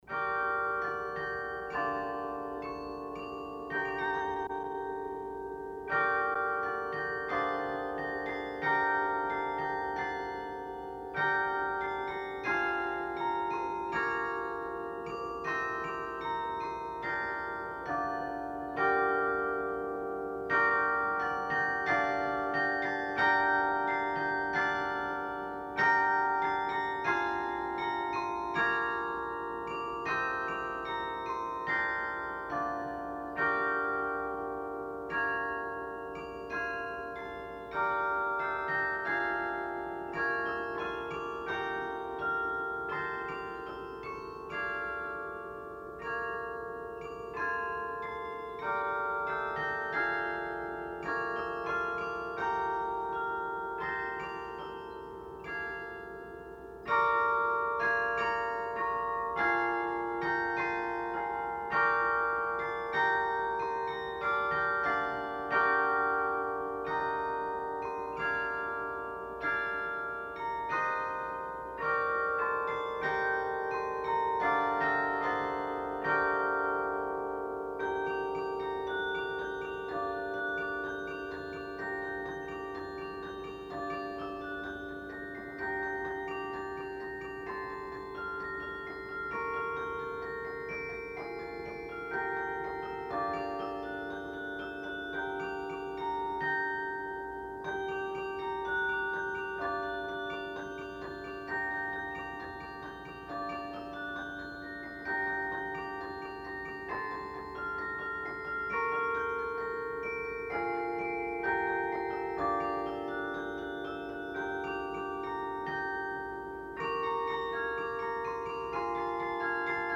Hopeman Carillon Concert